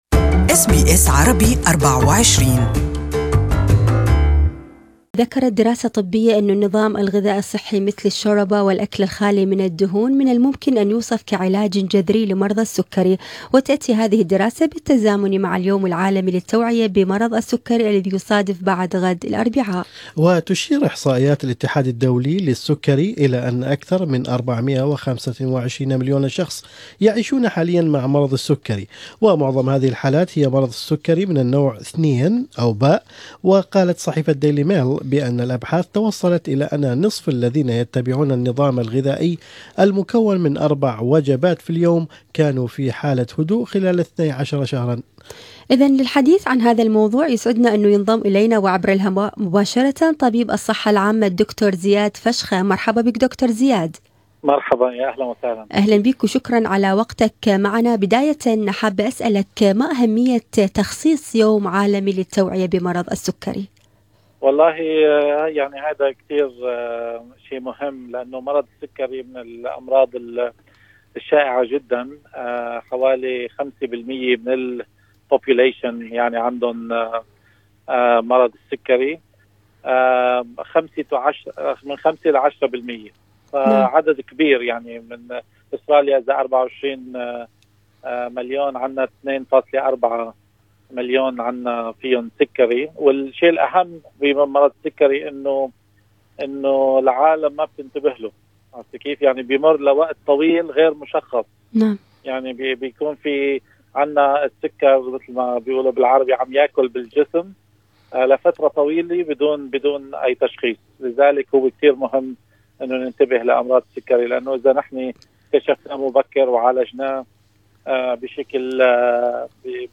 في حديث